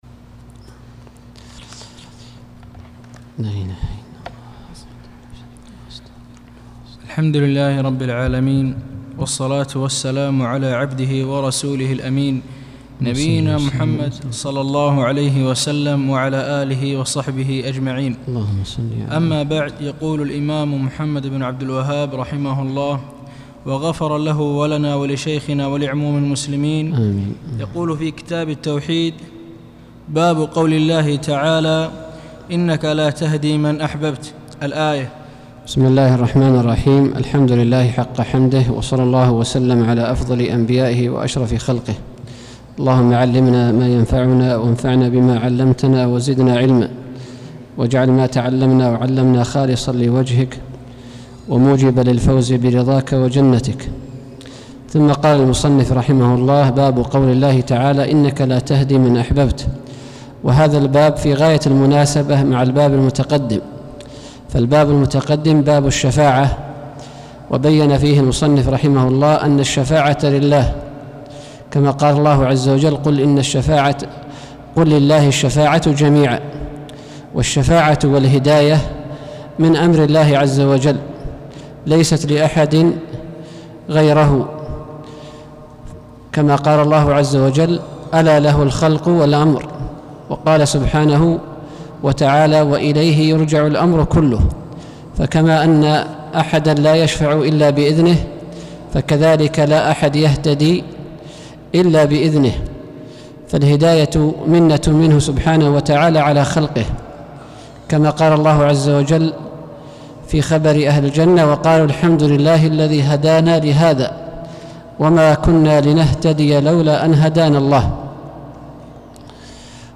الدرس الثامن عشر